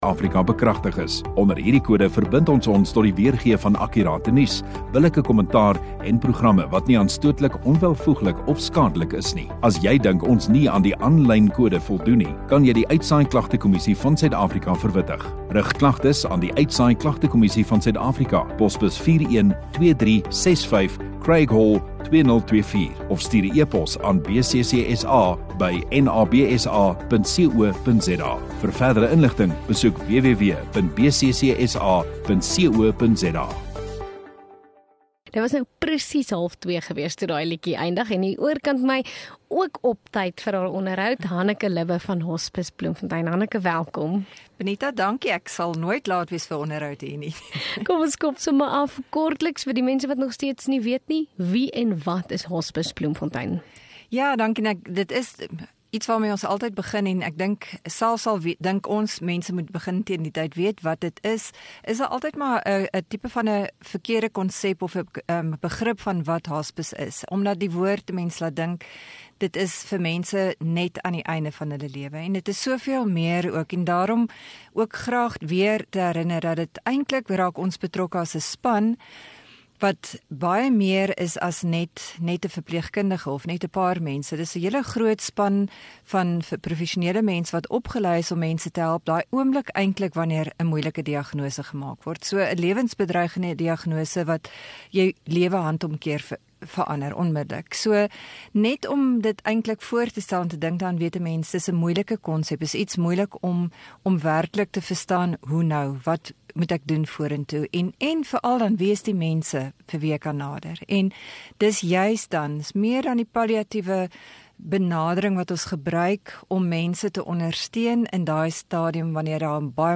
Radio Rosestad View Promo Continue Radio Rosestad Install Gemeenskap Onderhoude 9 Apr Hospice Bloemfontein